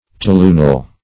Toluenyl \Tol`u*e"nyl\